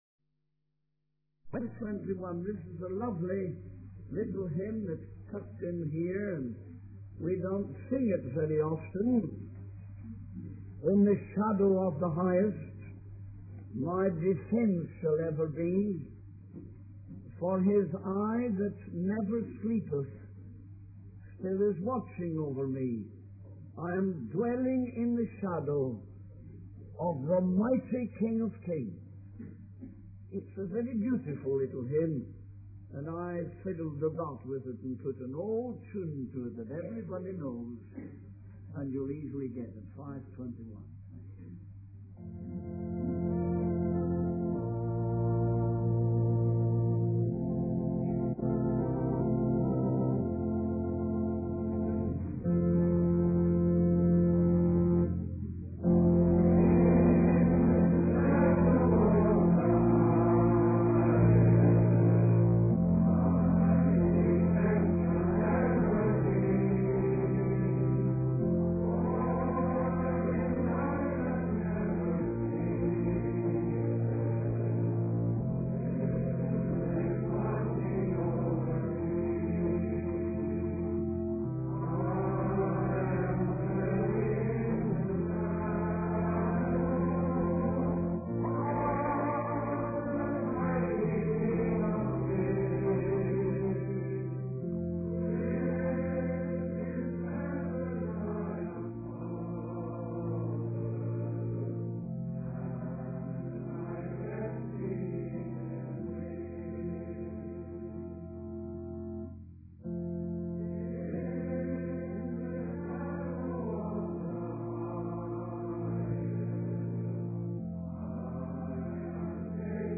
In this sermon, the speaker discusses a future scenario where a television is placed in every home and a man of authority speaks to the people, monitoring their actions. The speaker refers to the book of Daniel and focuses on the fourth beast and its ten horns. He explains that the fourth beast represents the Roman Empire, which had dominion over the entire earth.